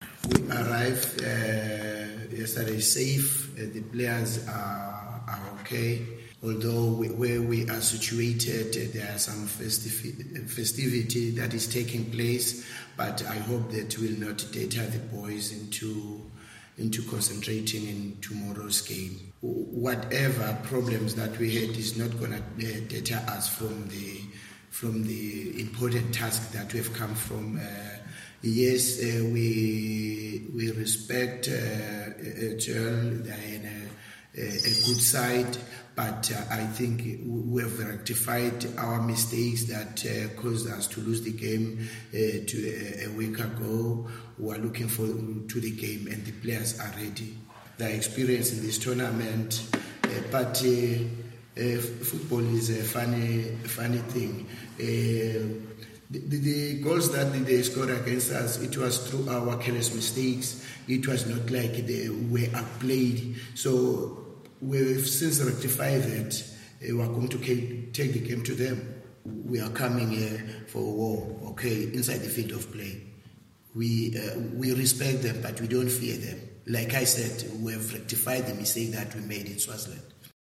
ندوة صحفية بقاعة المؤتمرات الصحفية بالملعب الأولمبي بسوسة